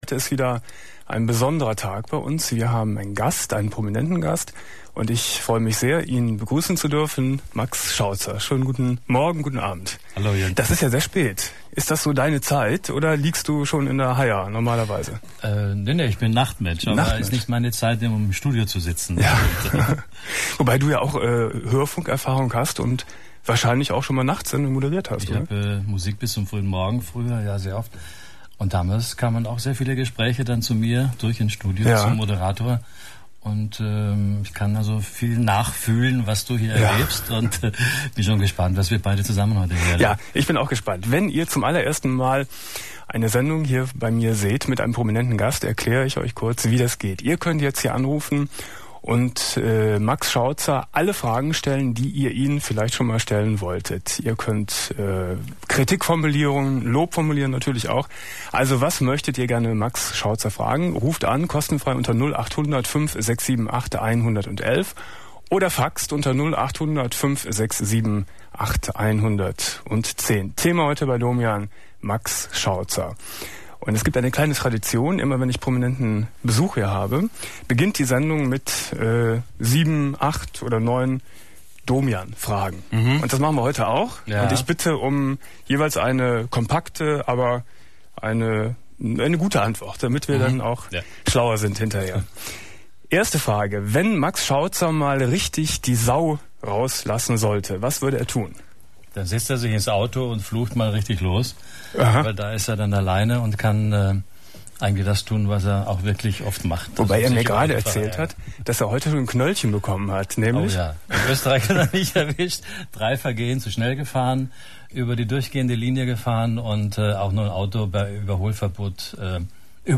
03.11.1999 Domian Studiogast: Max Schautzer ~ Domian Talkradio - Das Archiv Podcast